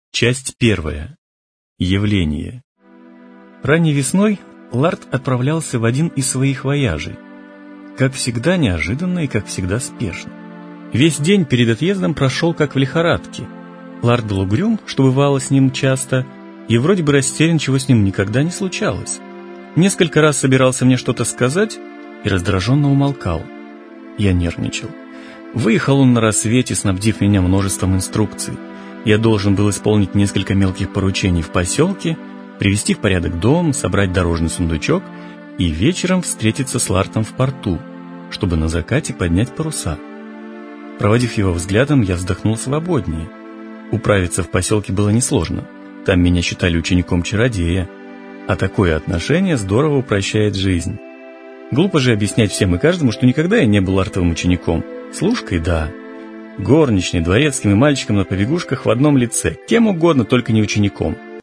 Аудиокнига Привратник | Библиотека аудиокниг